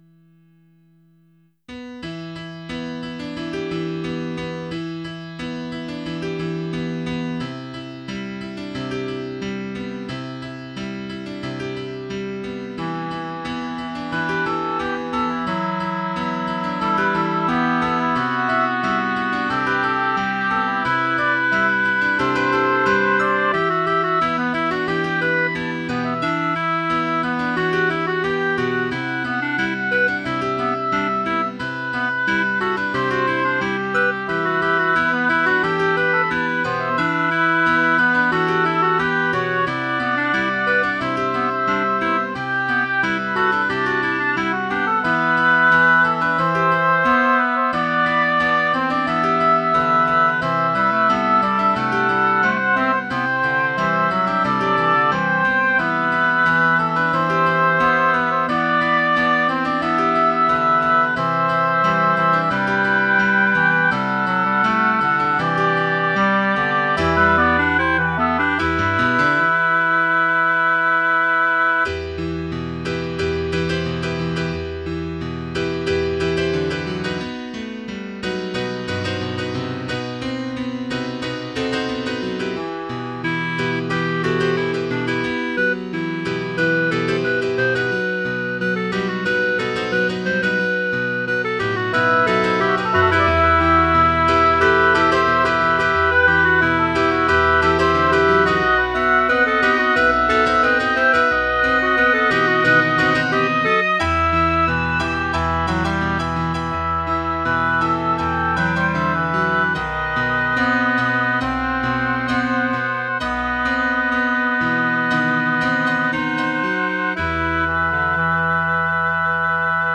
Tags: Piano, Clarinet, Woodwinds
Title Lazy Sunday Opus # 150 Year 2003 Duration 00:02:05 Self-Rating 4 Description A whizbang of all-over-the-place.